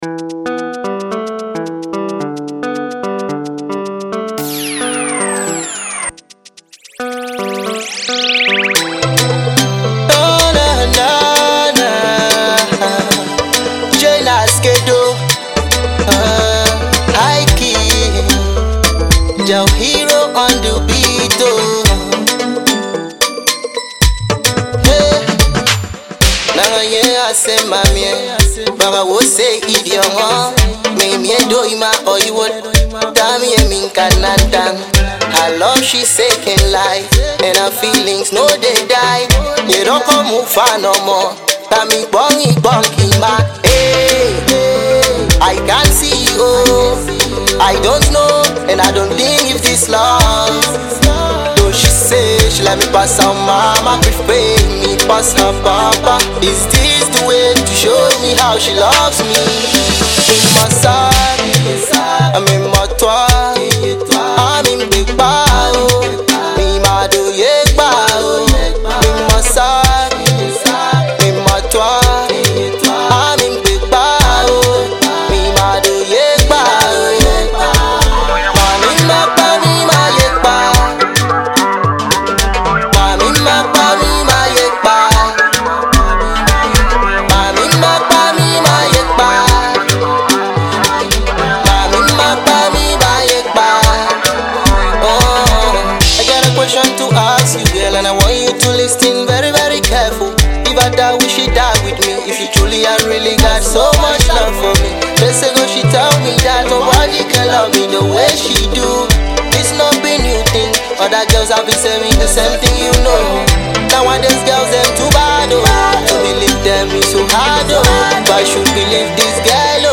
New School pop star